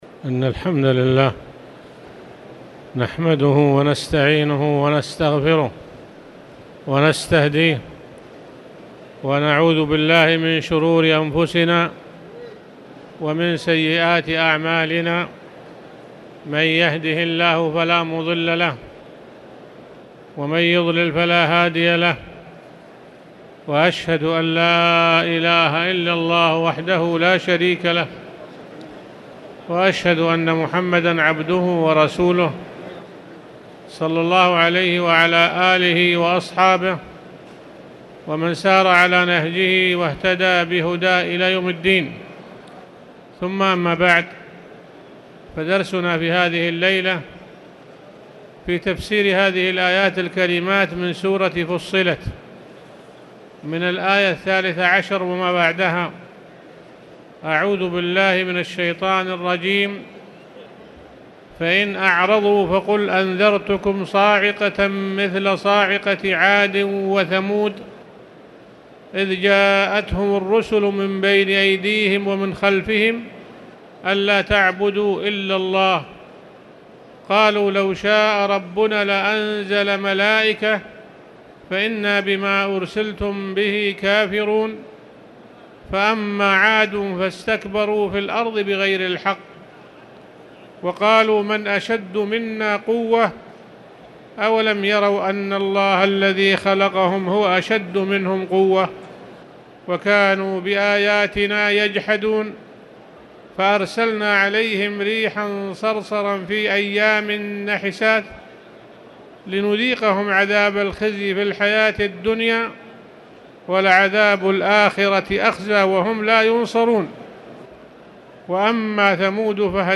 تاريخ النشر ١٣ رجب ١٤٣٨ هـ المكان: المسجد الحرام الشيخ